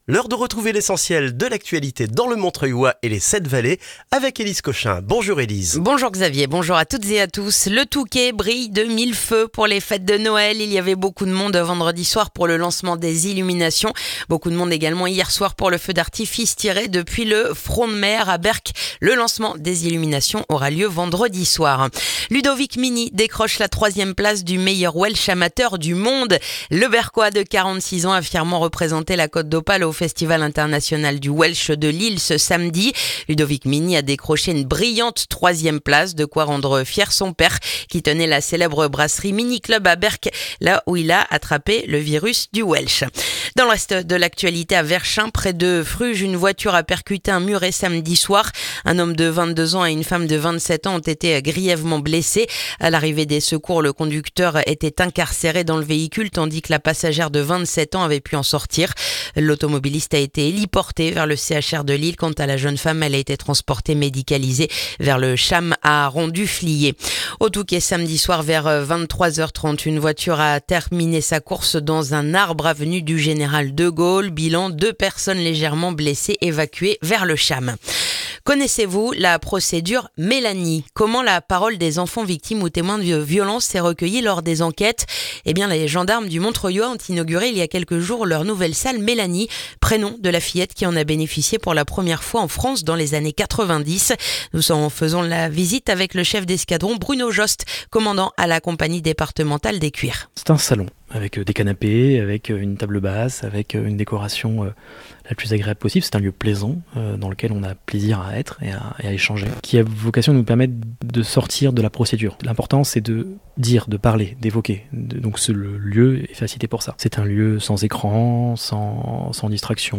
Le journal du lundi 1er décembre dans le montreuillois